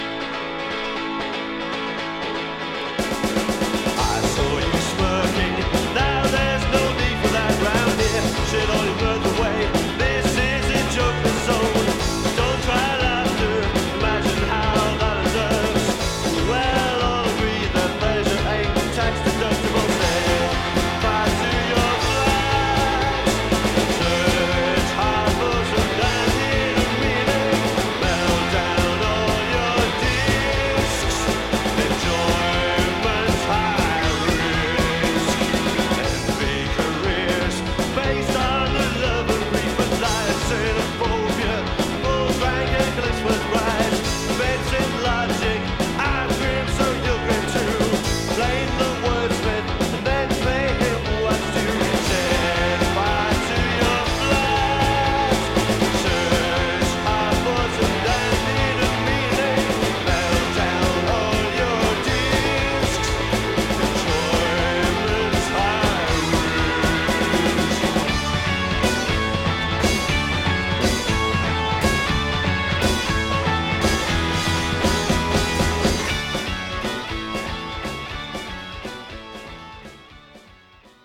文句なしのギターポップ!!
モータウンビートの縦ノリチューン